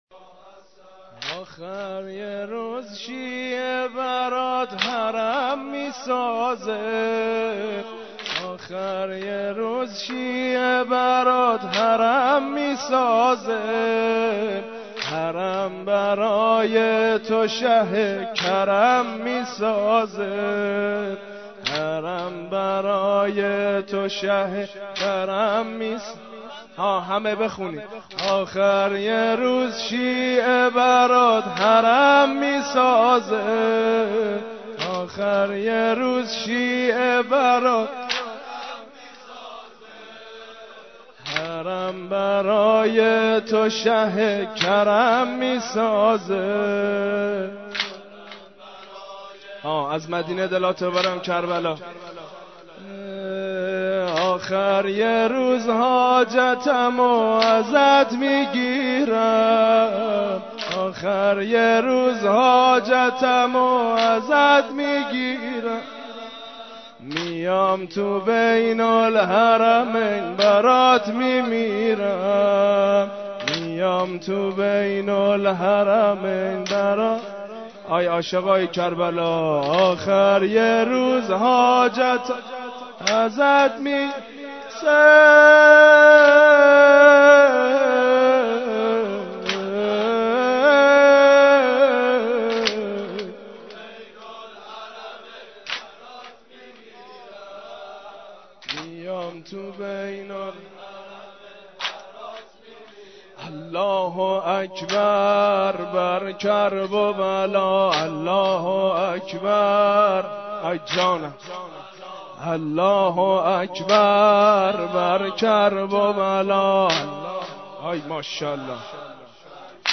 مراسم هفتگی؛ 26 صفر 1434؛ روضه حضرت سیدالشهداء و امام حسن مجتبی علیهما السلام؛ قسمت هفتم